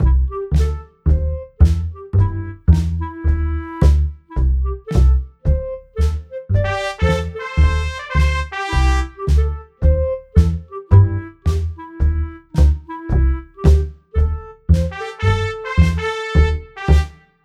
Lesson 8: Creating Jazz Music
lesson-8-example-jazz.wav